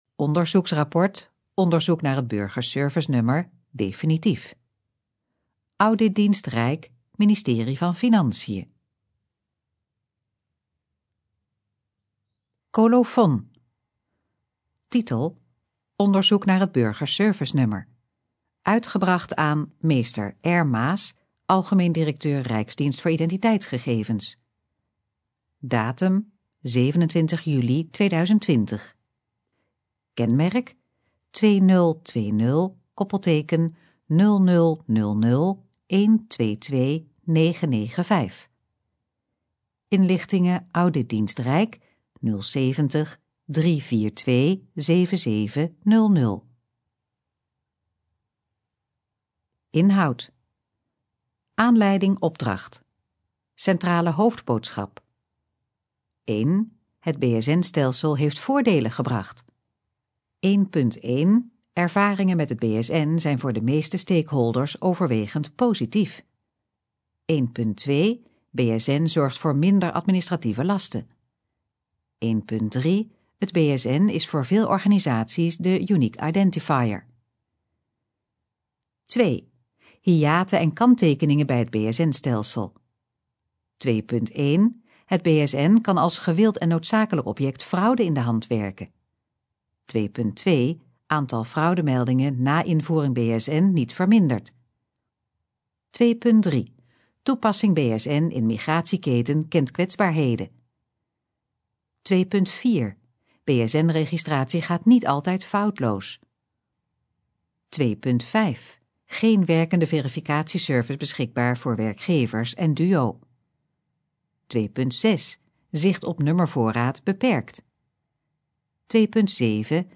Gesproken versie van het rapport